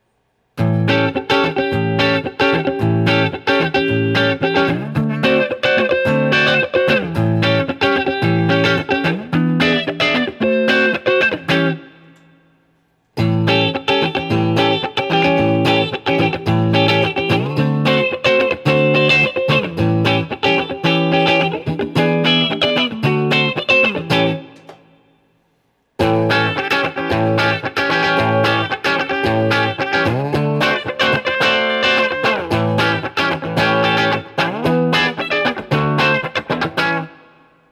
Every sound sample cycles from the neck pickup, to both, to the bridge pickup.
7th Chords
[/dropshadowbox]For these recordings I used my normal Axe-FX Ultra setup through the QSC K12 speaker recorded into my trusty Olympus LS-10.
If I had to be critical of the sound recordings, I’d say that the bridge pickup can be a bit harsh, though I think I could probably back that pickup away from the strings a bit to lower its output and probably clean that up if I really wanted to.
2000-Guild-Starfire4-BluesChords.wav